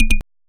switch_003.ogg